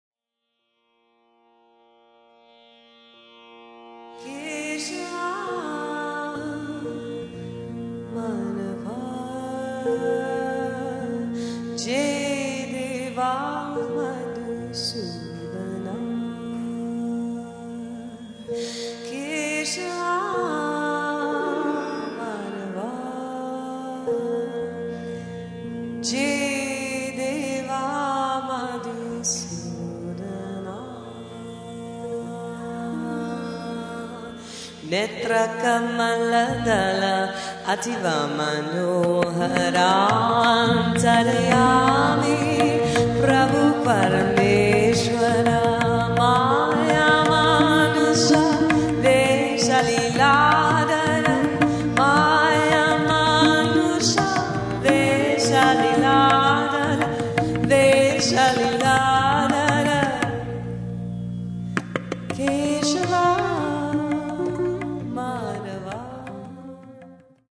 Indian Bhajan with jazz influences
vocals, violin, sitar, double bass, tabla